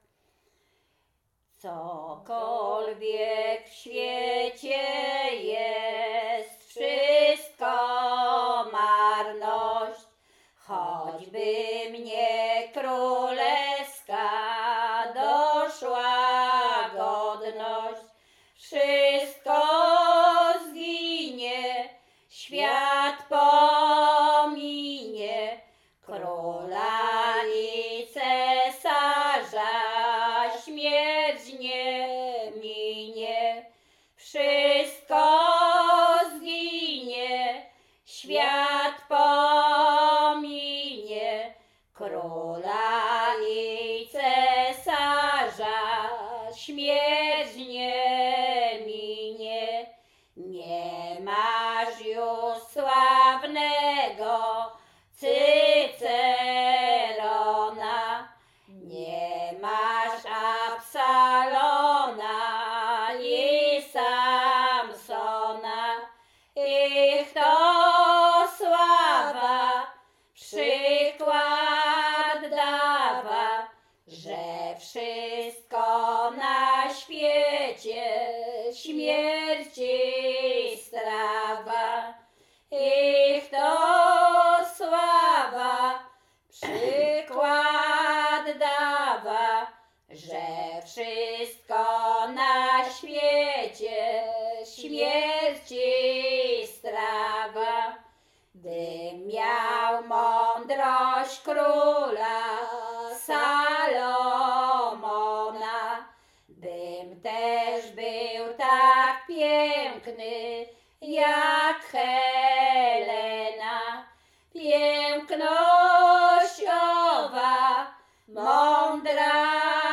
Śpiewaczki z Czerchowa
Łęczyckie
Pogrzebowa
pogrzebowe nabożne katolickie do grobu